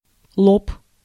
Ääntäminen
IPA: /pænæ/